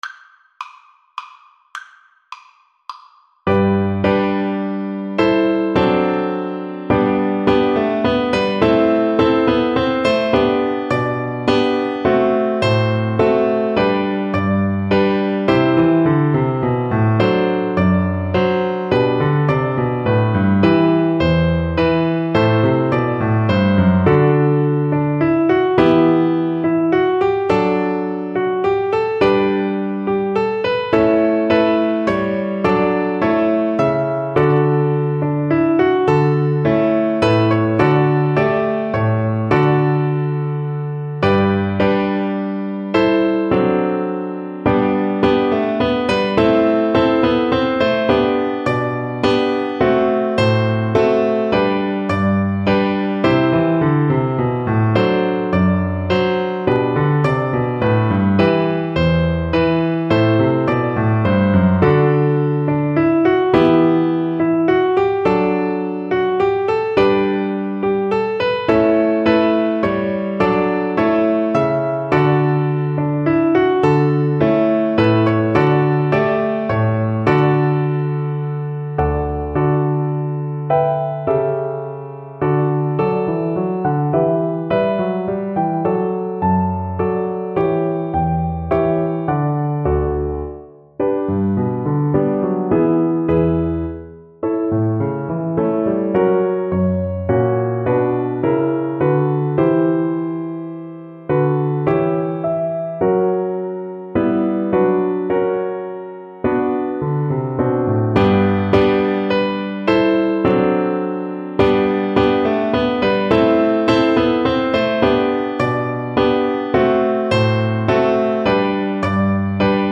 3/8 (View more 3/8 Music)
Classical (View more Classical Cello Music)